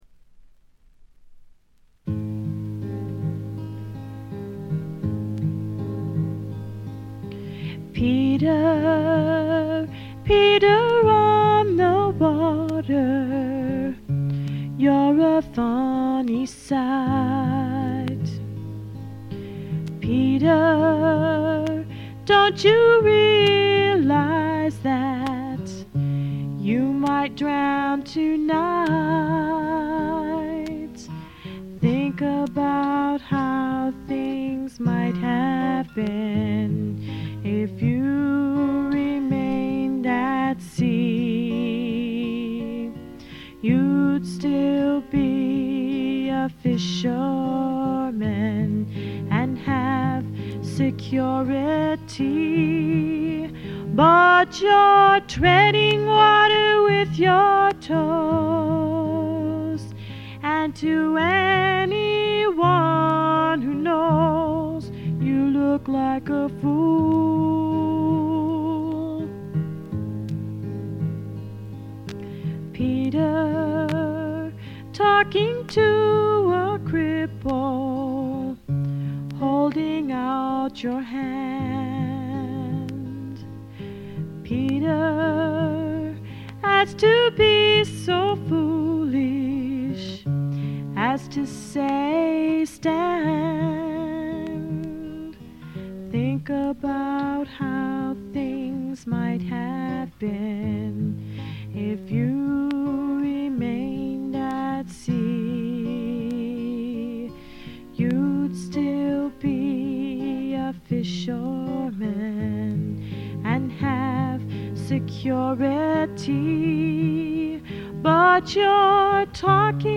A3の序盤と中盤で軽い周回ノイズ。
知られざるクリスチャン・フォーク自主制作盤の快作です。
試聴曲は現品からの取り込み音源です。